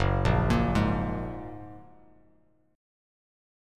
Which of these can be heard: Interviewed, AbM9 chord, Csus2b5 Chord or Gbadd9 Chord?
Gbadd9 Chord